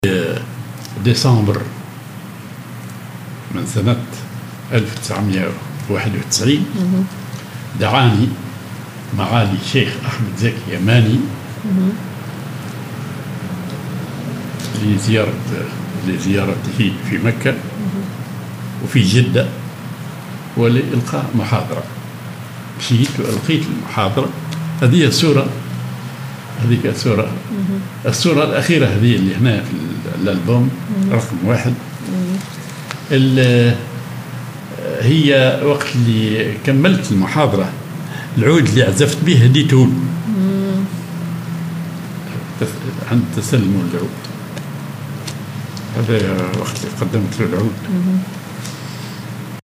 زيارة الاستاذ صالح المهدي لمعالي الشيخ أحمد زكي اليماني في مكة/جدة و إلقاء محاضرة
تسليم الأستاذ صالح المهدي للشيخ أحمد زكي اليماني العود الذي عزف به أثناء إلقاء المحاضرة